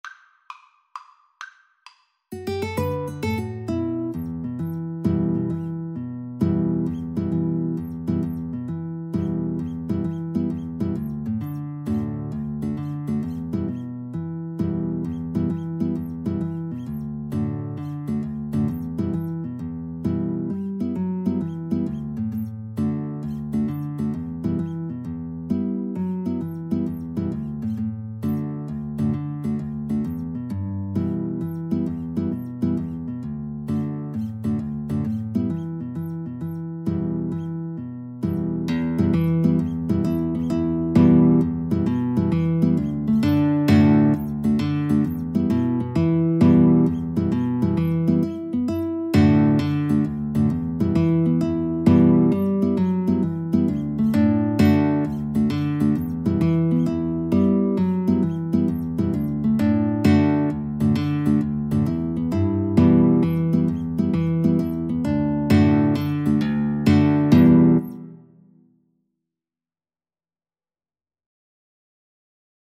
E minor (Sounding Pitch) (View more E minor Music for Violin-Guitar Duet )
rocky_road_VNGT_kar1.mp3